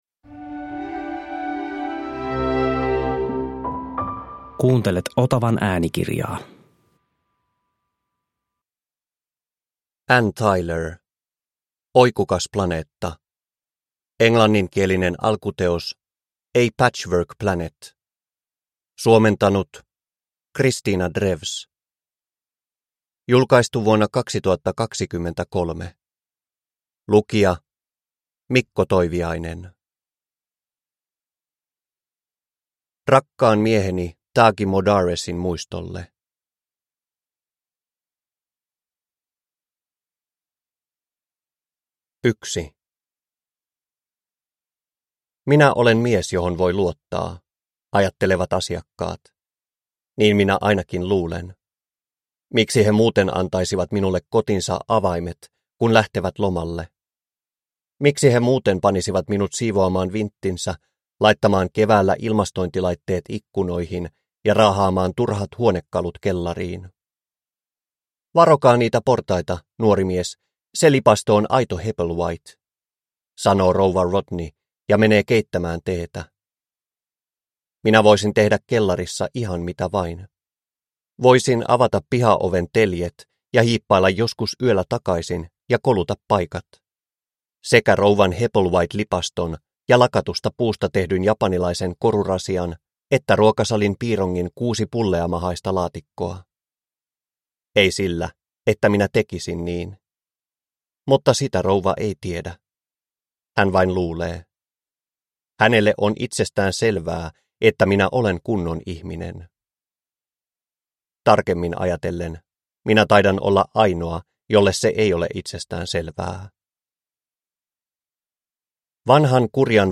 Oikukas planeetta – Ljudbok – Laddas ner